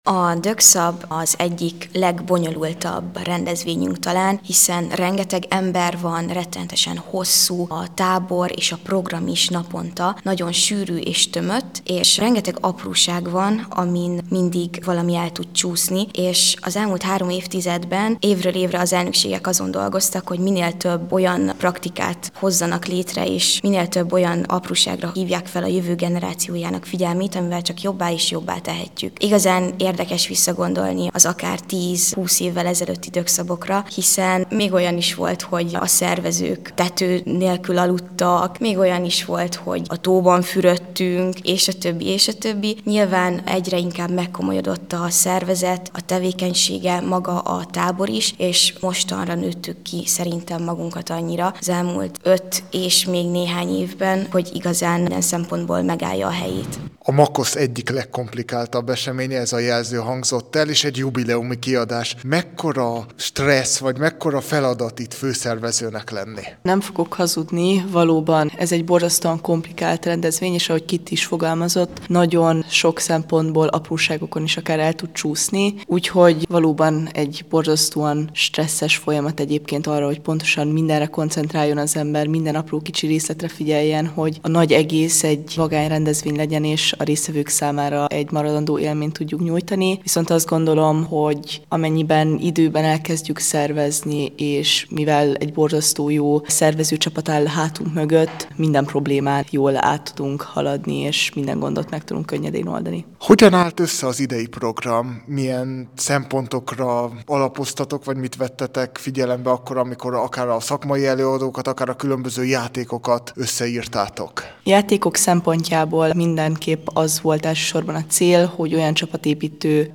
Javában zajlik a DÖKSZAB 30. kiadása a Maros megyei Jobbágyfalván.